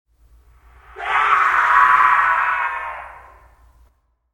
veryfar_noise.ogg